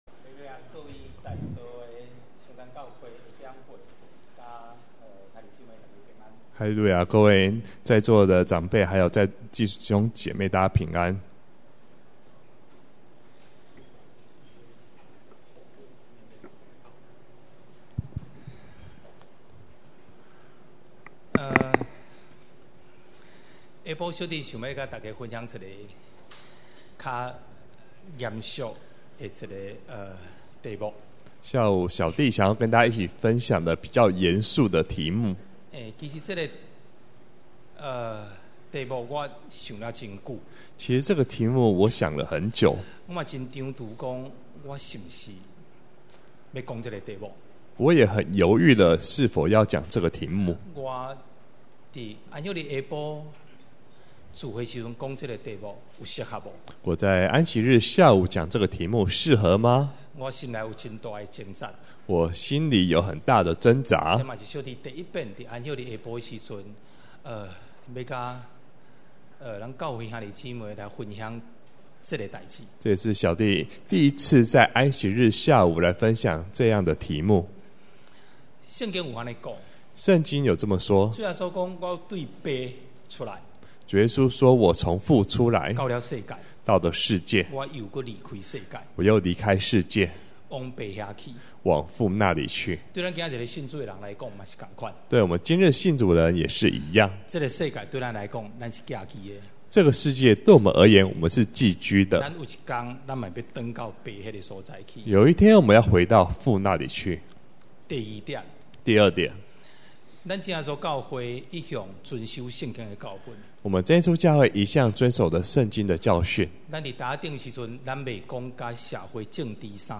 2018年10月份講道錄音已全部上線